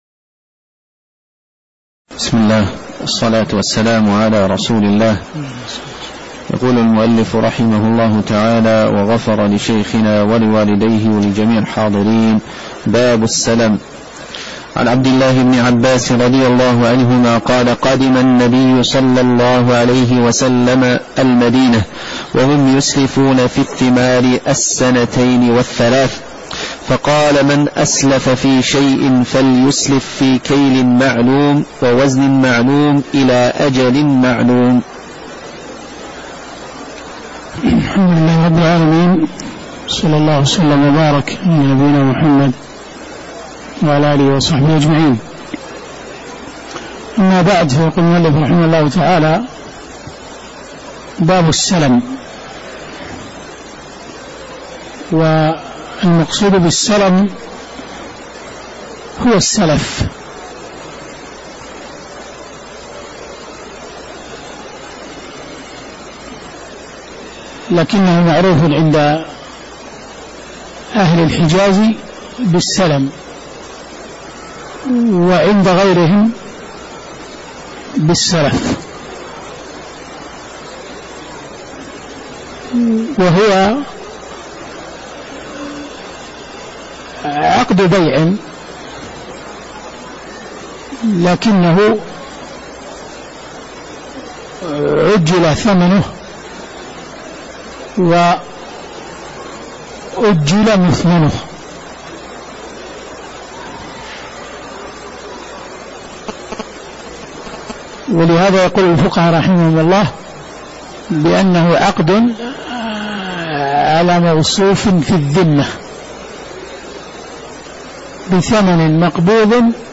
تاريخ النشر ٢٥ محرم ١٤٣٩ هـ المكان: المسجد النبوي الشيخ